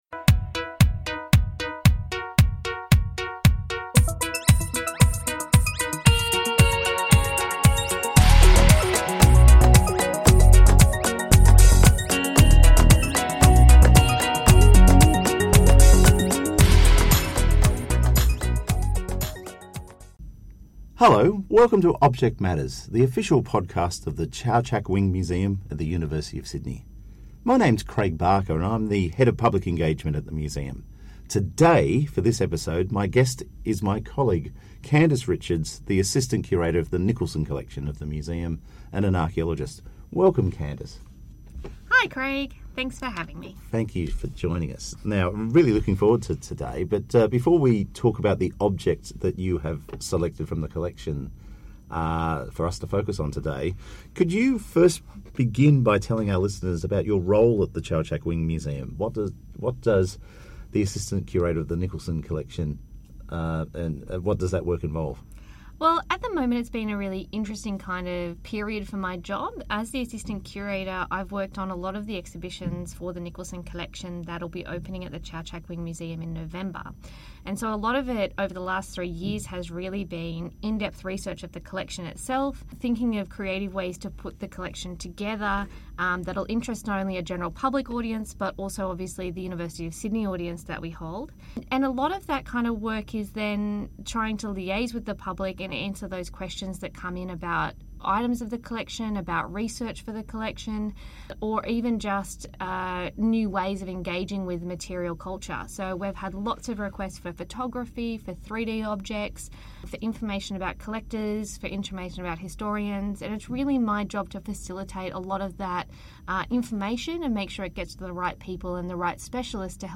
Together they discuss a ‘Promesse de mandat territorial'; a French bank note issued in 1796, two years before the Napoleonic campaign in Egypt.